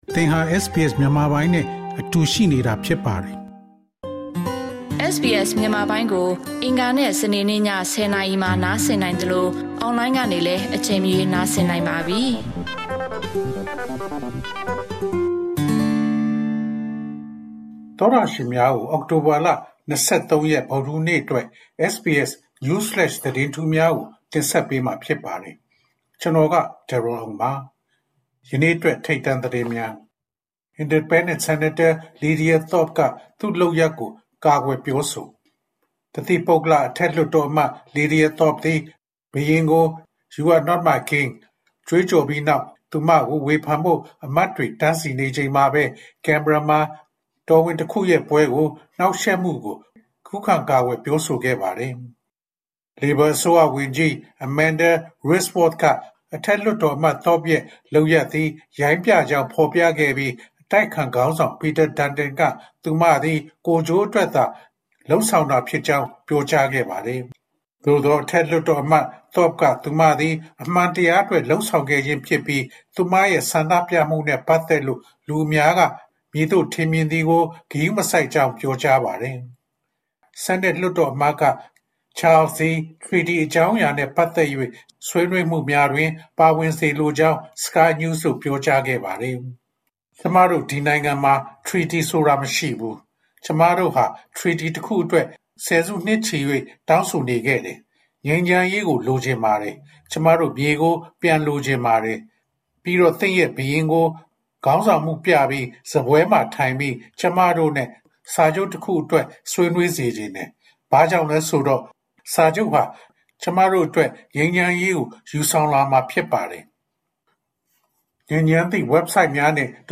SBS မြန်မာ ၂၀၂၄ နှစ် အောက်တိုဘာလ ၂၃ ရက် News Flash သတင်းများ။